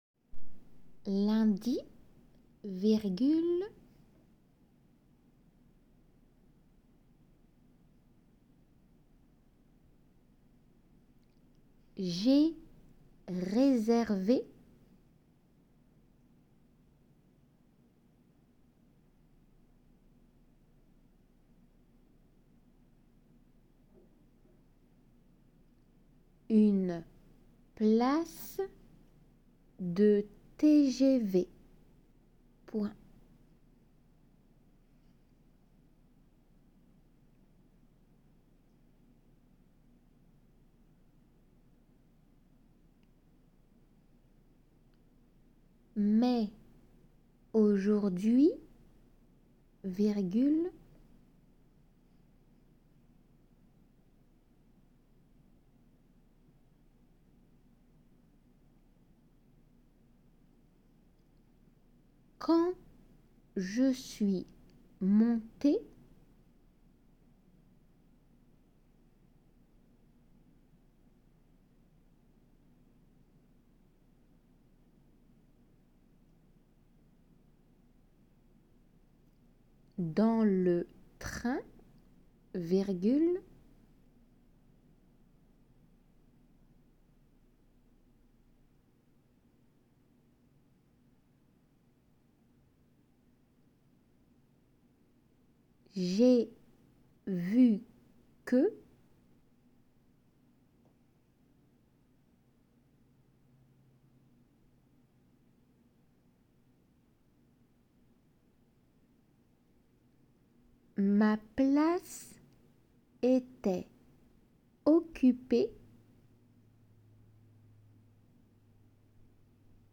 仏検2級　デイクテ　練習ー3　音声
このホームページでは録音者の都合で実際の試験場での　読み手に則さずの録音になって